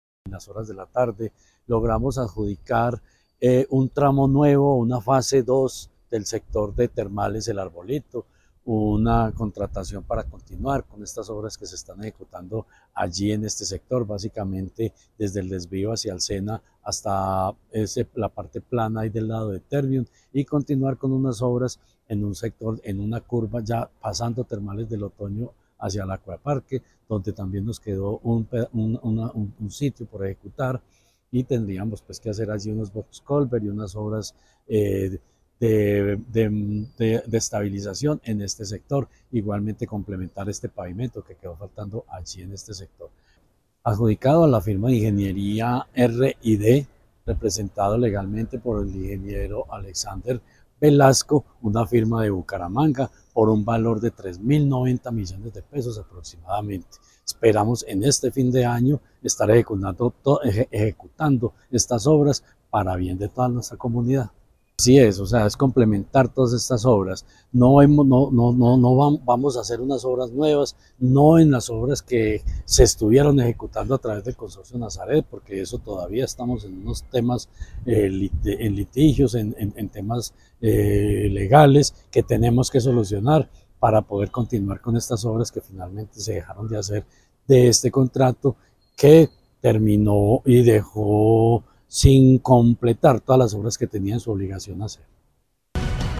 Secretario de Infraestructura de Caldas, Jorge Ricardo Gutiérrez.